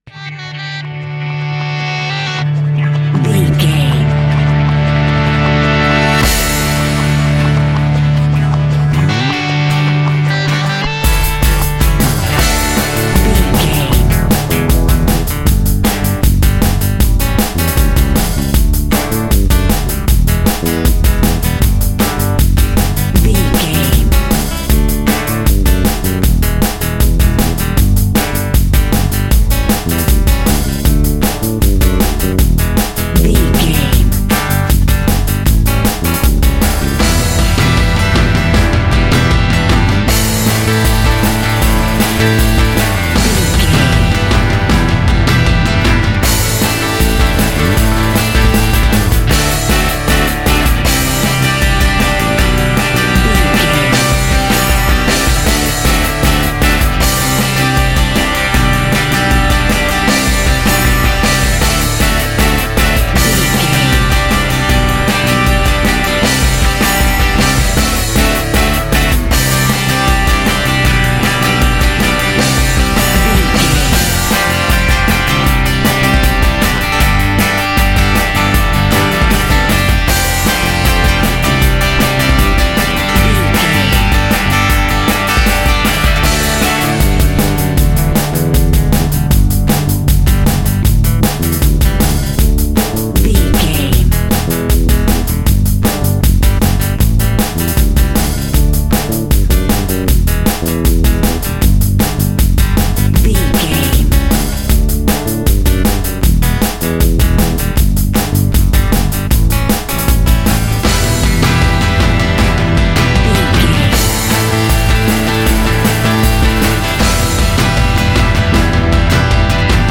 60s Pop Rock.
Ionian/Major
D
fun
energetic
uplifting
drums
bass guitar
piano
hammond organ
synth